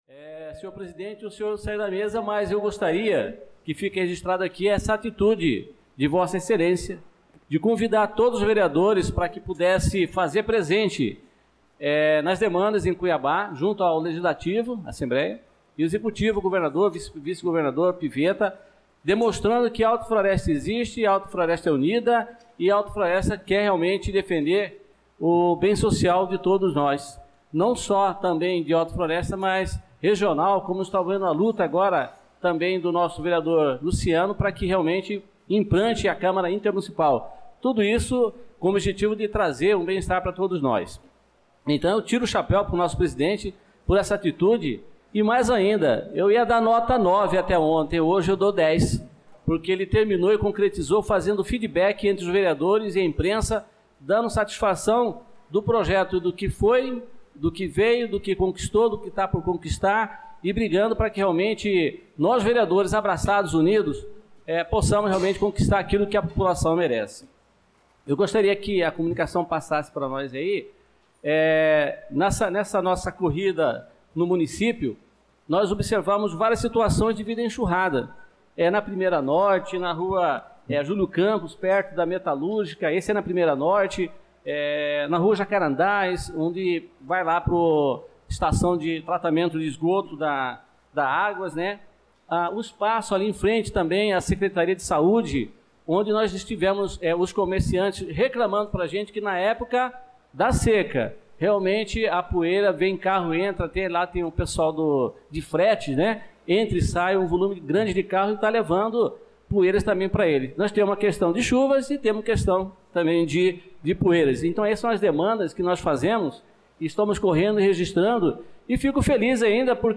Pronunciamento do vereador Adelson na Sessão Ordinária do dia 18/02/2025